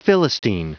Prononciation du mot philistine en anglais (fichier audio)
Prononciation du mot : philistine